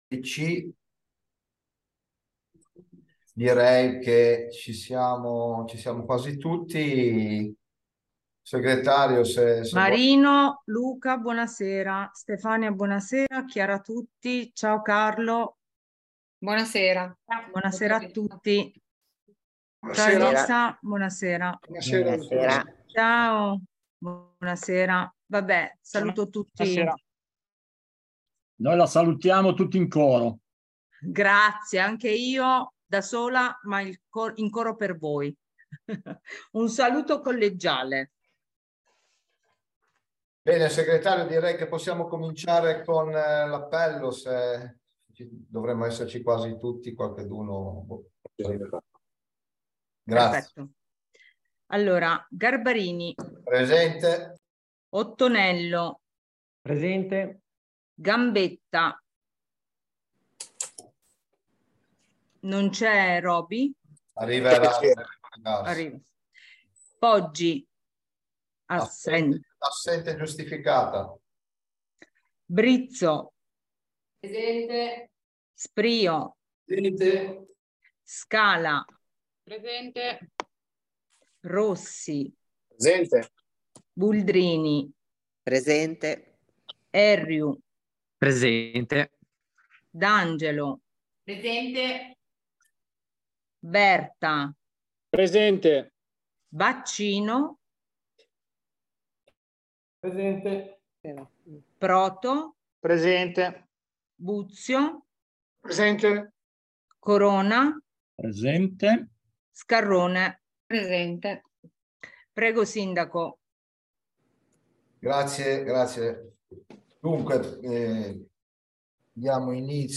Consiglio comunale del 27 settembre 2023 - Comune di Albisola Superiore
Seduta del Consiglio comunale mercoledì 27 settembre 2023, alle 18.30, in videoconferenza, è stato discusso il seguente ordine del giorno: Approvazione e validazione del verbale della seduta di Consiglio comunale del 16.08.2023 ai sensi dell’art. 60 del regolamento del Consiglio comunale. Comune di Albisola Superiore – approvazione del bilancio consolidato al 31/12/2022.